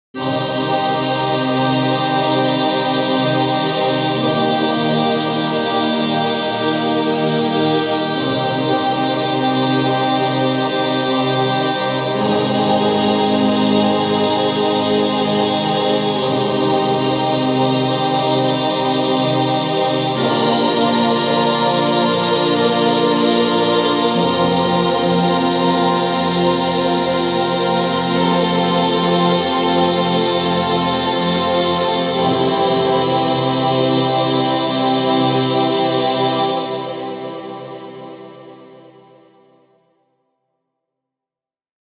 Solemn Choral Singing Intro Music
Genres: Sound Logo